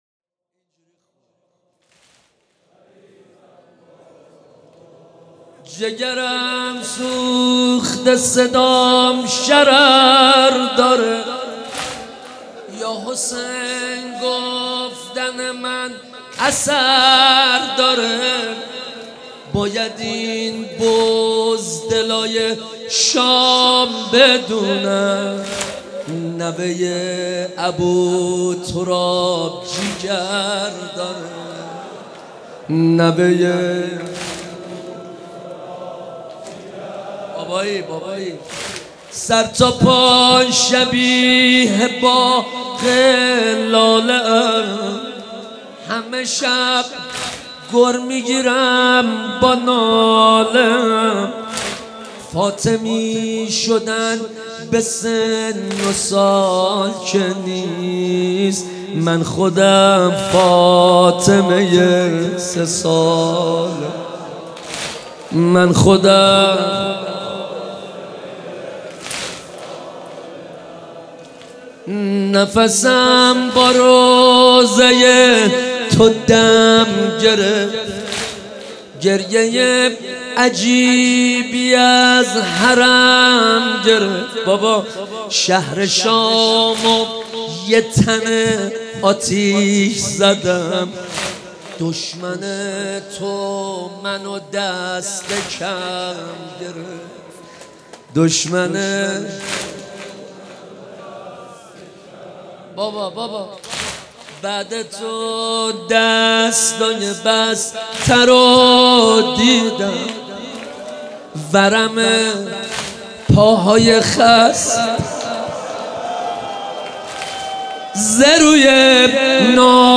مناسبت : شب سوم محرم